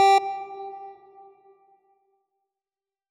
G1.wav